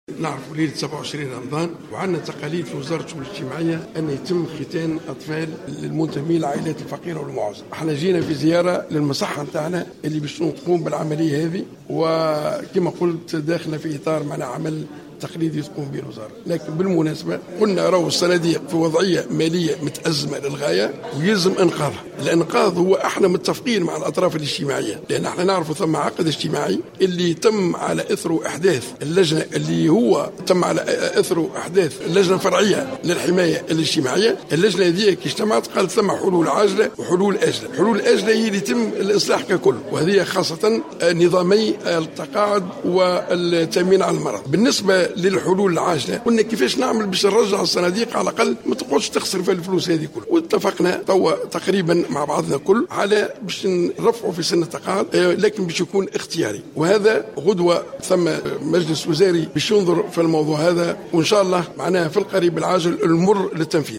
Le ministre des Affaires sociales, Ahmed Ammar Younbaï, a indiqué lors d’une déclaration accordée à Jawhara FM aujourd’hui 13 juillet 2015, que son administration est ouverte au dialogue et à la négociation avec le syndicat des pharmaciens.
Ammar Younbai a ajouté, en marge de sa présence à la cérémonie de circoncision des enfants des familles démunies à Bizerte, que le système du tiers payant selon lequel le Tunisien ne paie que 30% de la valeur globale du médicament est encore valide pour une année, précisant qu’il n’existe aucun malentendu entre le syndicat des pharmaciens et le ministère.